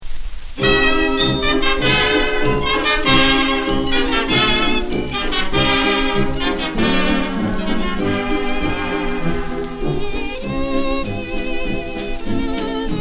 Here's an example clip of a hissy source: